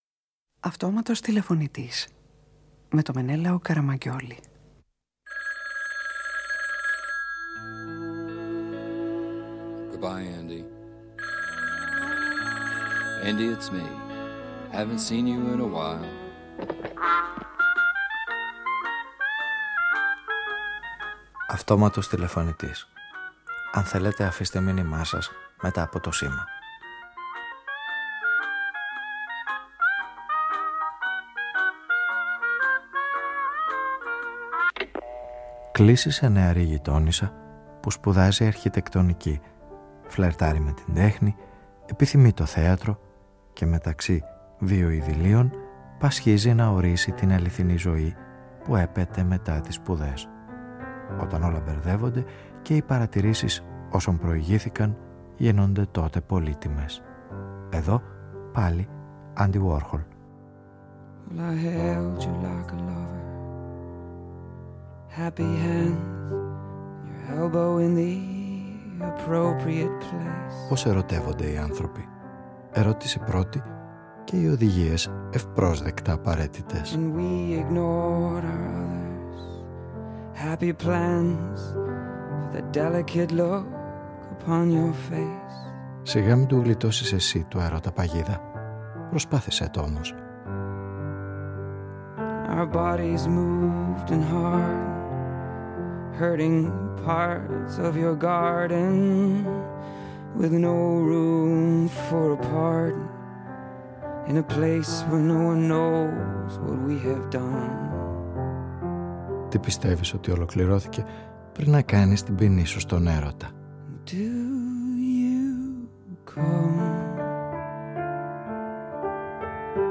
Η ηρωίδα αυτής της ραδιοφωνικής ταινίας σπουδάζει αρχιτεκτονική, επιθυμεί το θέατρο και μεταξύ δύο ειδυλλίων πασχίζει να ορίσει την αληθινή ζωή που έπεται μετά τις σπουδές. Η καίρια κι ανατρεπτική παρέμβαση του Αντι Γουόρχολ άραγε θα την βοηθήσει να βρει άκρη ή θα την μπερδέψει πιο πολύ;
Παραγωγή-Παρουσίαση: Μενέλαος Καραμαγγιώλης